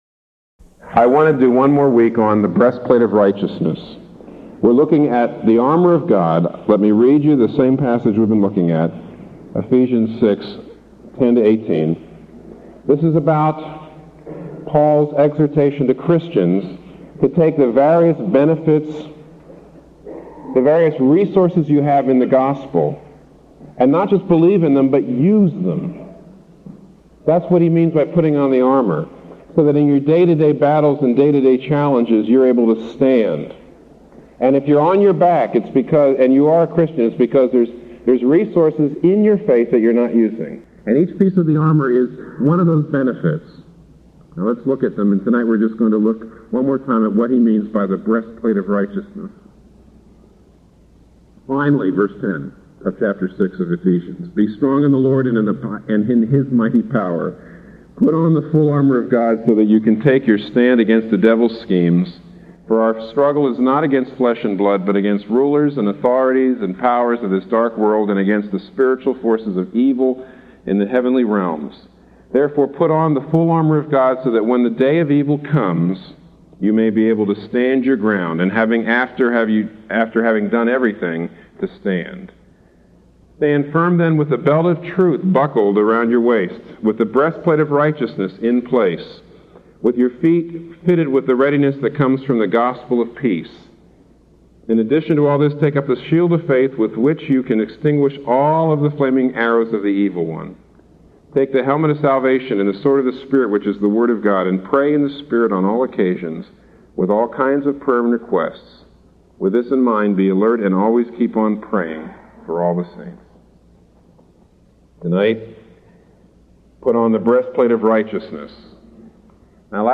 The following sermons are in MP3 format.